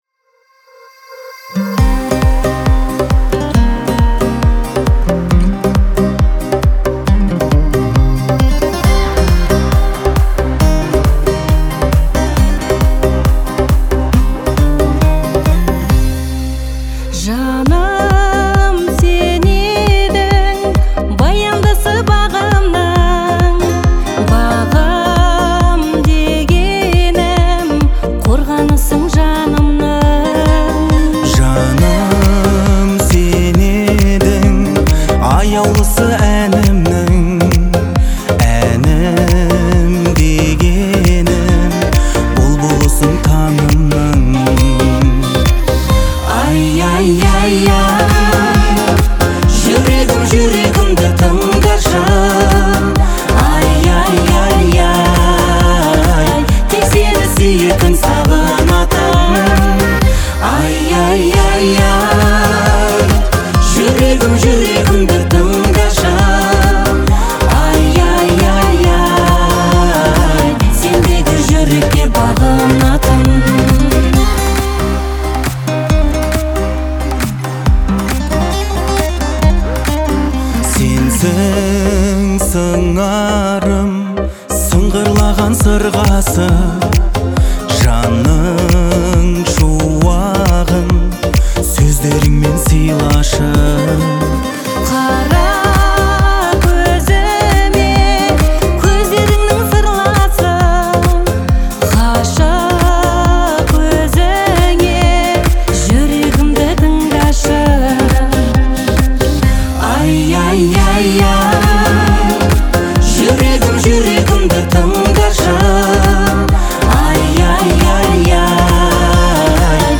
трогательная дуэтная песня